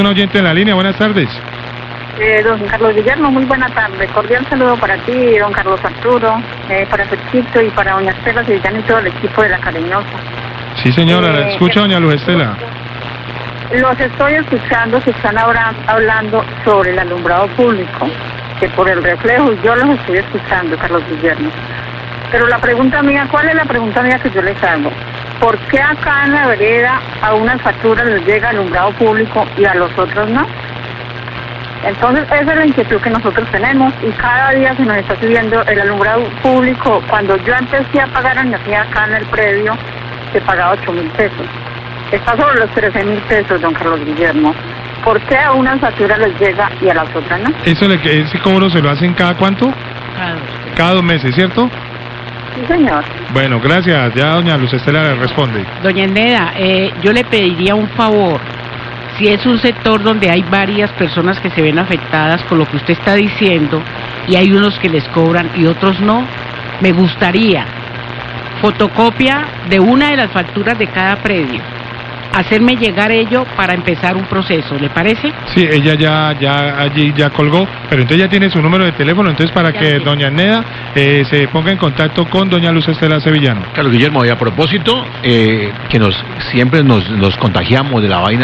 OYENTE DE LA ZONA MONTAÑOSA SE QUEJA POR EL COBRO DEL ALUMBRADO PÚBLICO, LA CARIÑOSA, 1211PM
Radio